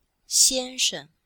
Cours-de-chinois-xian1sheng5-f.oga